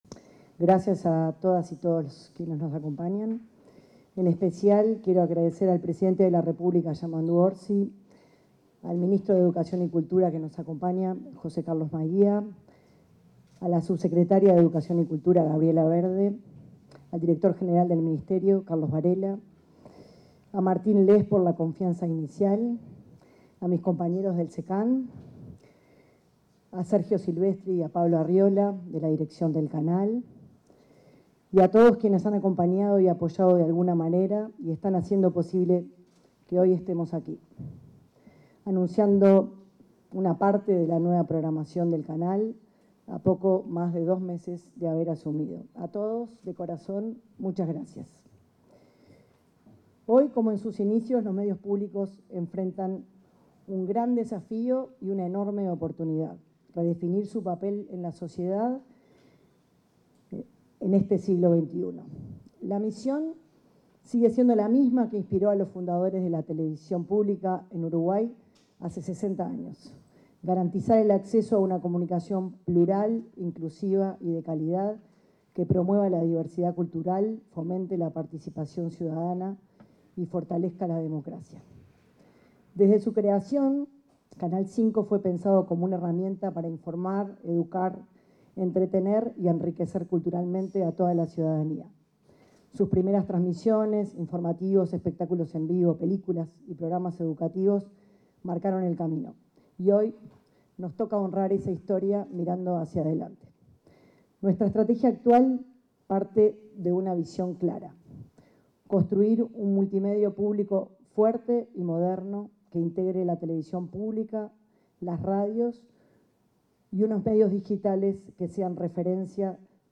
Palabras de autoridades en la presentación de la programación de Canal 5
La presidenta del Servicio de Comunicación Audiovisual Nacional (Secan), Erika Hoffmann, y el ministro de Educación y Cultura, José Carlos Mahía,
Oratorias.mp3